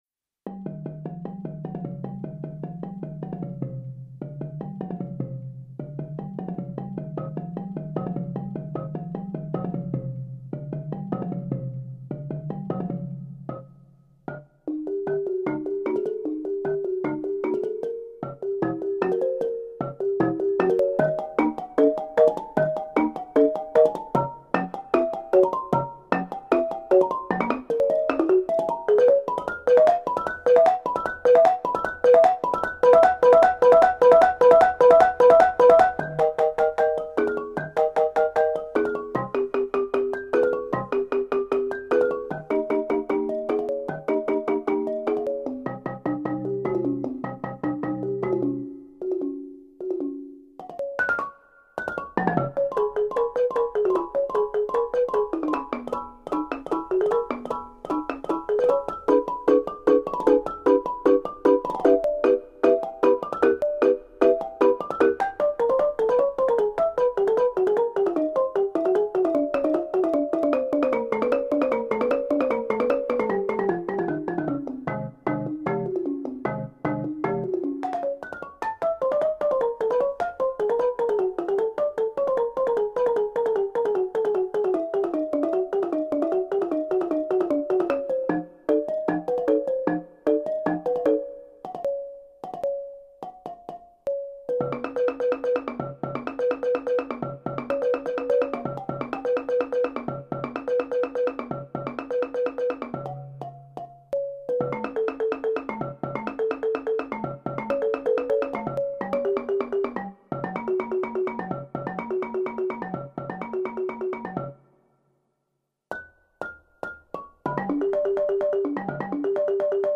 joué sur le balafon